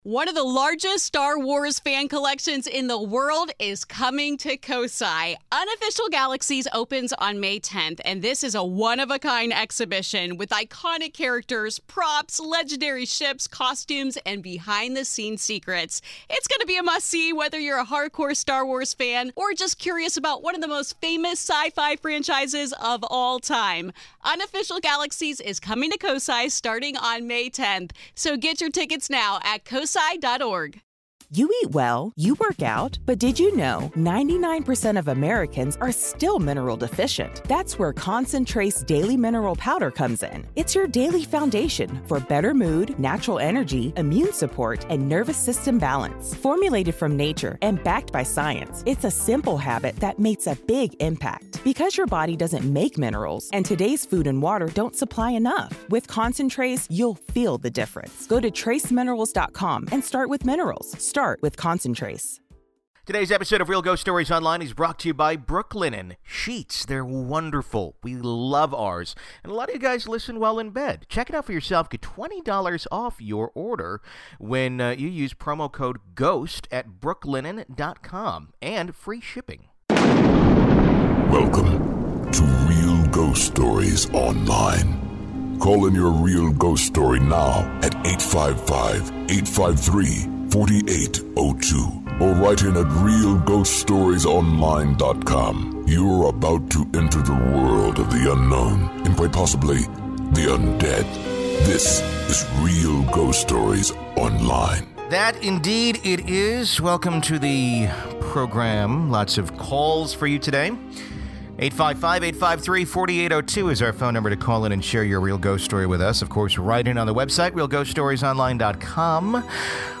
We hear stories from several people who experience paranormal events yet fail to believe in ghosts.